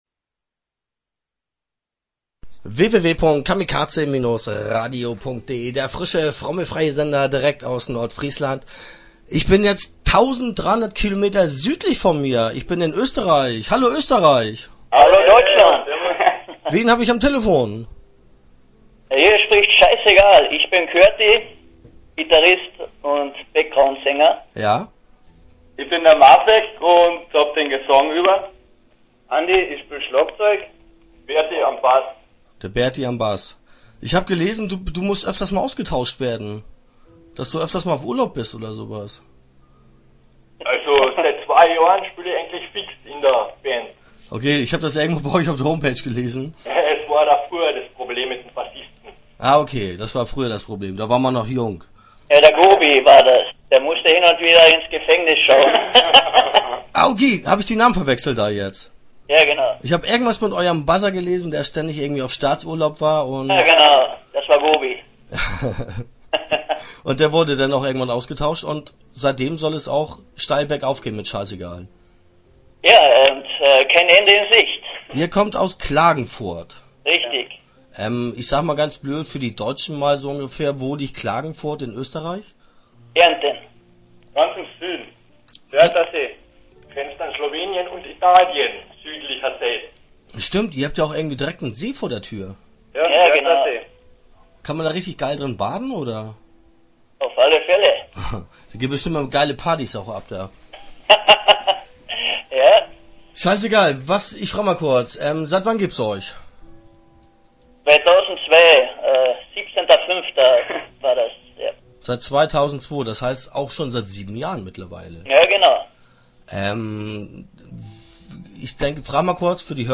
Start » Interviews » Scheissegal